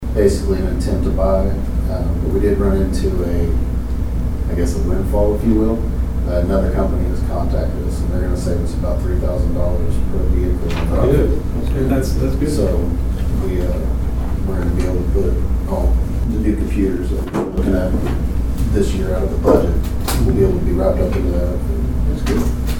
The Dewey City Council convened on Monday evening at Dewey City Hall for the final time in September.
Police Chief Jimmy Gray talked about a recent change with the police cars that were involved in the proposition.